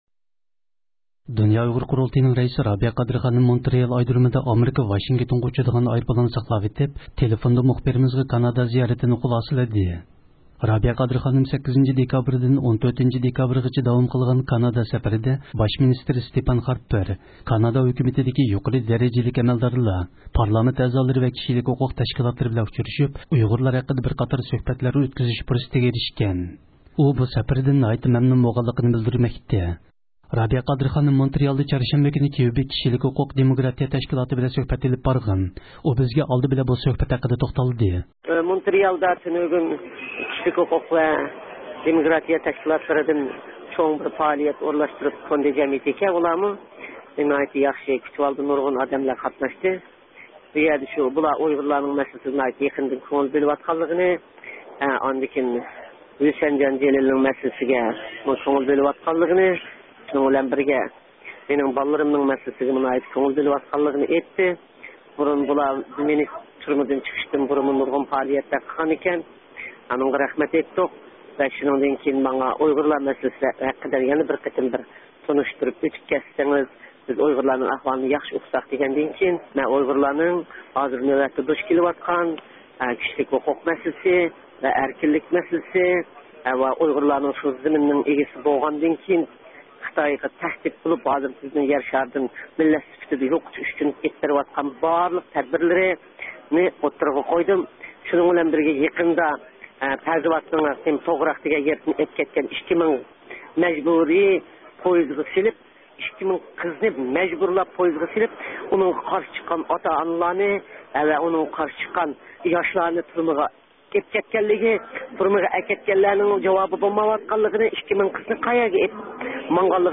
دۇنيا ئۇيغۇر قۇرۇلتىيىنىڭ رەئىسى رابىيە قادىر خانىم مونترېئال ئايدۇرۇمىدا ئامېرىكا ۋاشىنگتونغا ئۇچىدىغان ئايروپىلاننى ساقلاۋېتىپ تېلېفوندا مۇخبىرىمىزغا كانادا زىيارىتىنى خۇلاسىلىدى.